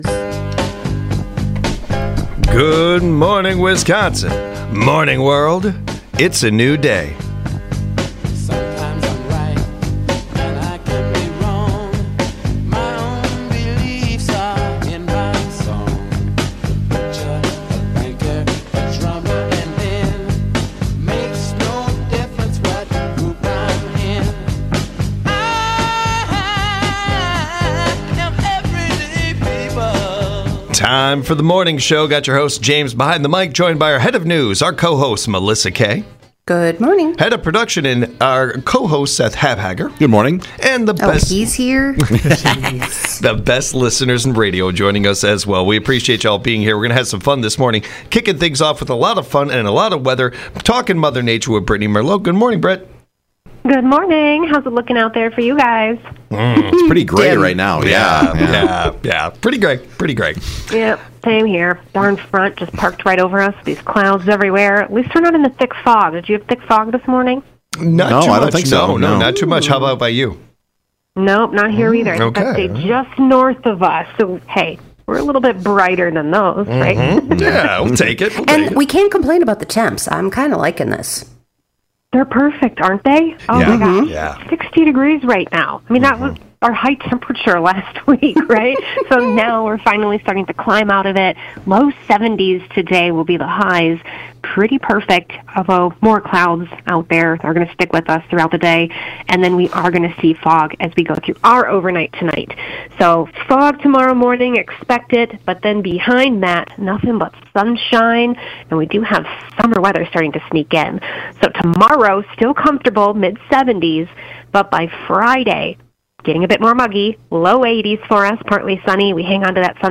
Girl Scouts have released this year’s cookie lists. The team discusses the new, the old, and the favorites. When is it time to ‘trust your gut’?